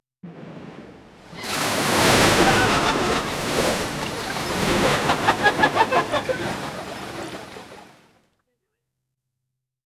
waves crashing , ship crew laughter , ballet comid cong
waves-crashing--ship-crew-fu3hbexb.wav